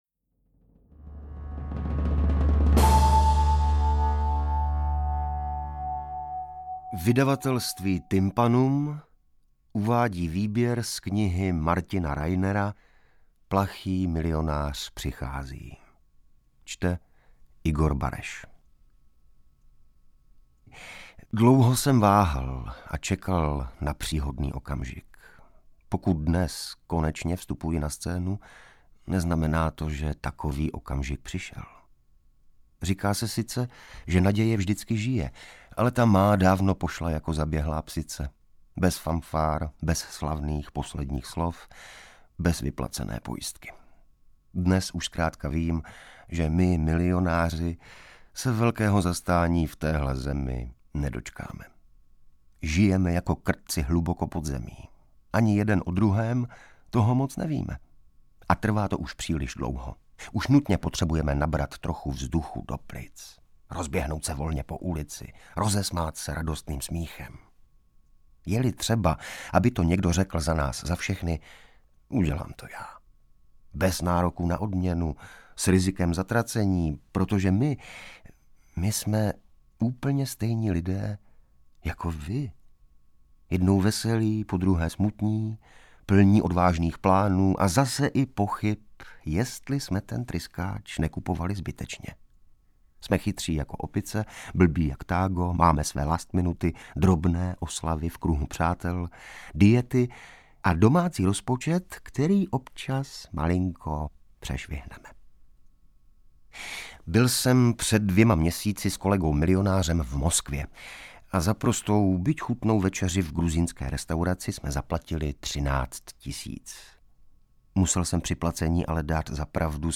Interpret:  Igor Bareš